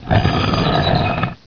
doorGrate.wav